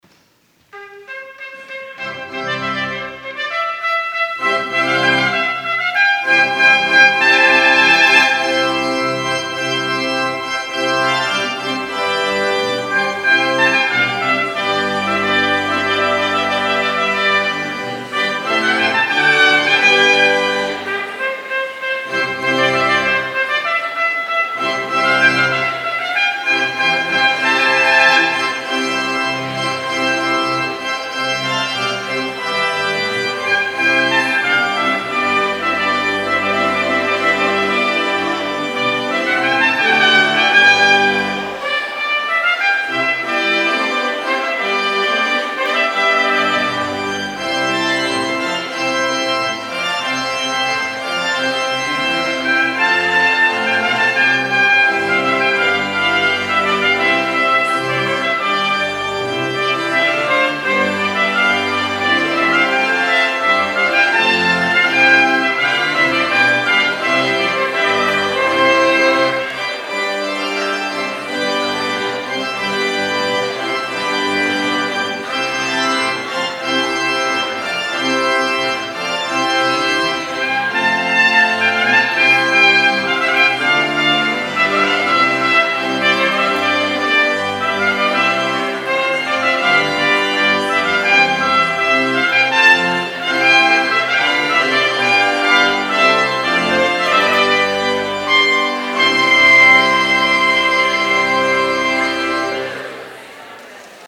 POSTLUDE The Rejoicing
trumpet
organ